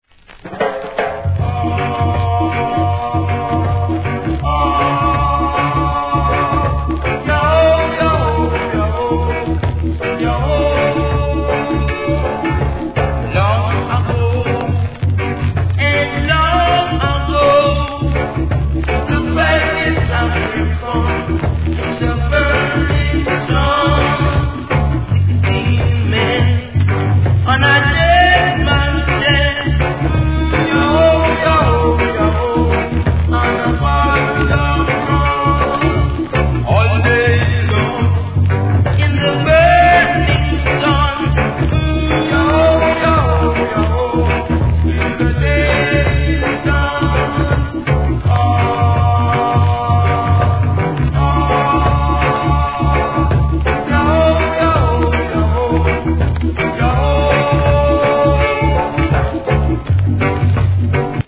REGGAE
「ヤオ〜ヤオ〜ヤオ〜♪」のコーラスに生かされている人間の魂を感じます。